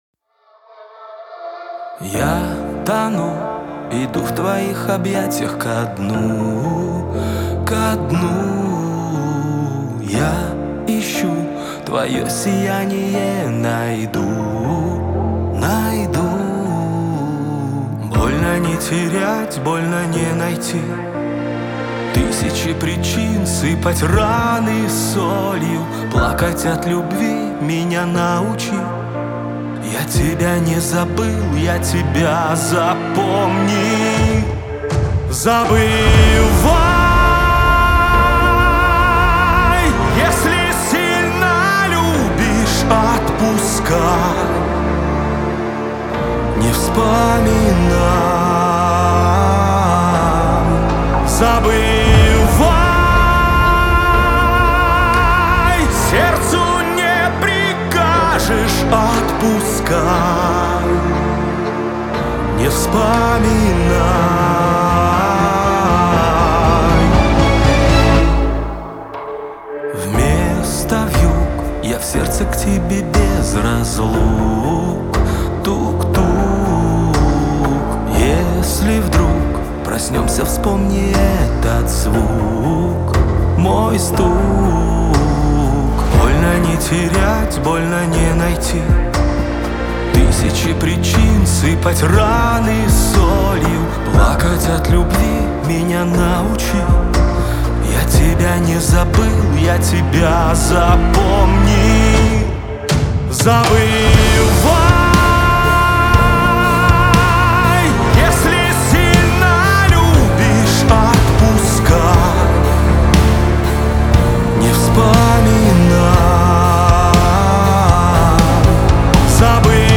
романтическая баллада
выполненная в жанре поп-музыки.
Настроение композиции – меланхоличное, но с нотками надежды.